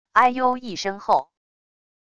哎呦一声后wav音频